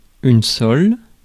Ääntäminen
IPA: /sɔl/